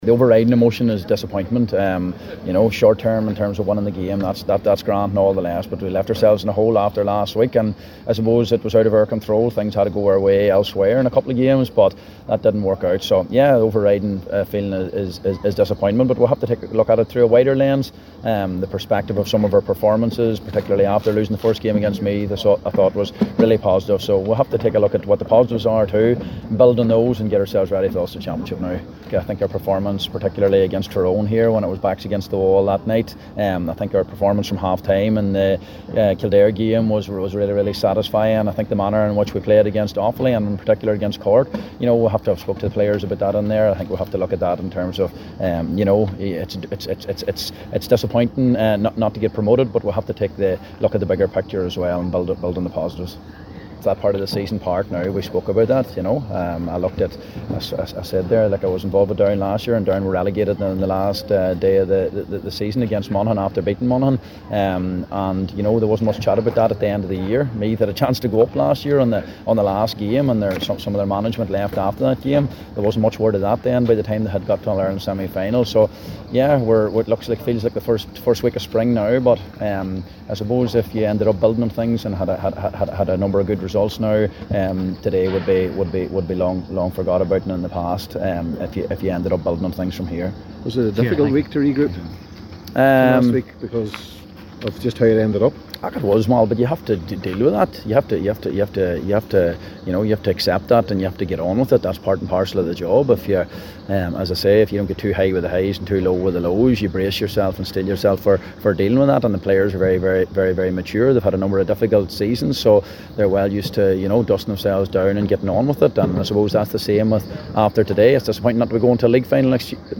spoke to the assembled media